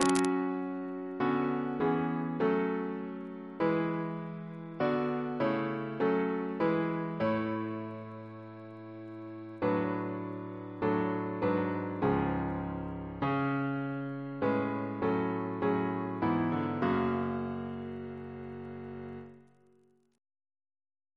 Double chant in F♯ minor Composer: Chris Biemesderfer (b.1958) Note: for the Song of Hannah